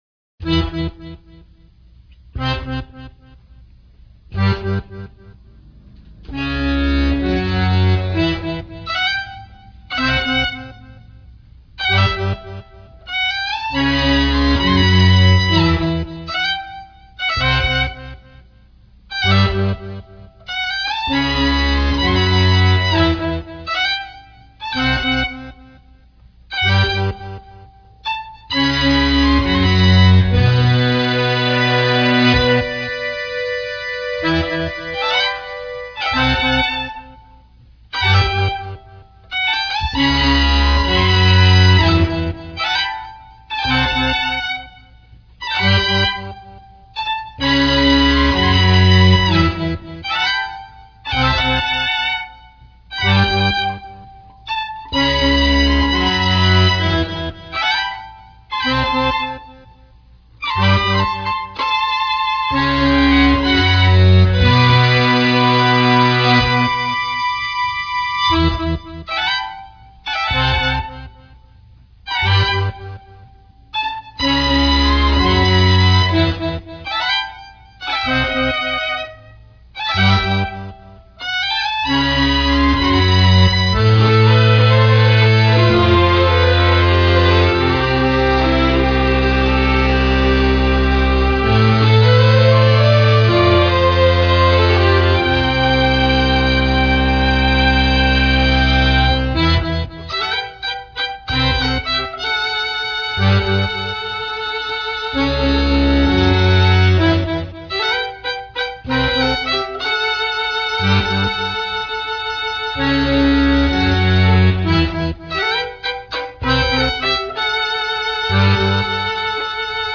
Musical excerpt